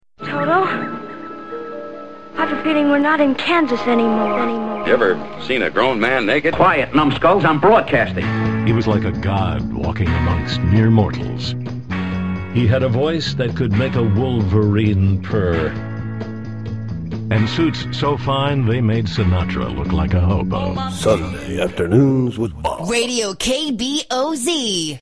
KBOZ Opening Liner
Tags: Talk Radio Internet Radio Comedy Prank Calls Live Show